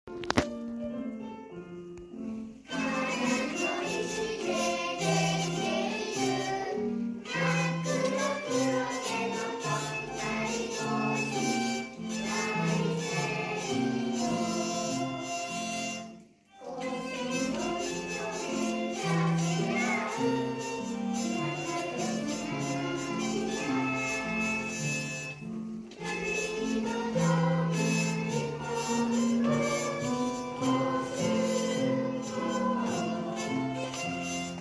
ピュイ・ド・ドームの自然や地域の魅力を歌い上げたこの校歌は、小さな子どもたちにも親しみやすい内容で、開校以来、多くの人々に愛され続けています。録音された校歌は、学習発表会の場で生徒によるハーディ・ガーディの演奏に合わせて歌われました。
ピュイ・ド・ドーム日本語補習授業校校_発表会校歌.mp3